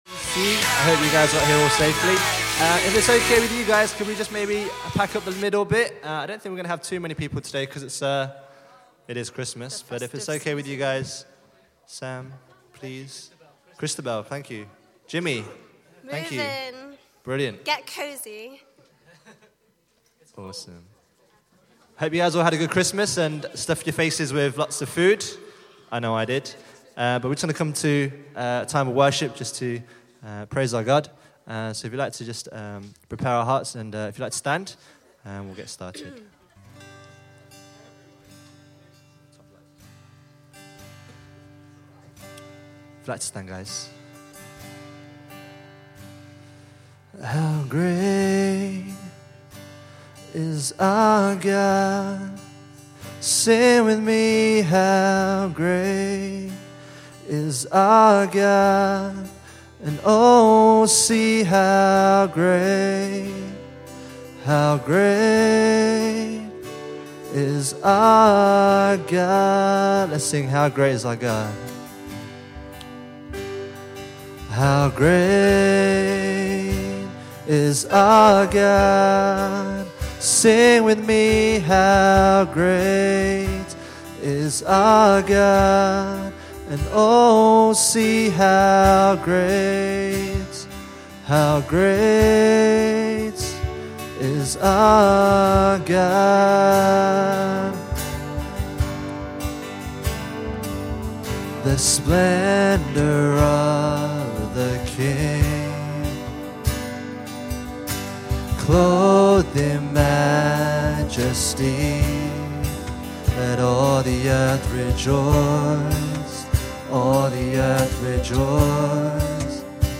December 28 – Worship – Birmingham Chinese Evangelical Church
december-28-worship